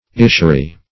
Search Result for " ischury" : The Collaborative International Dictionary of English v.0.48: Ischury \Is"chu*ry\, n. [L. ischuria, Gr.
ischury.mp3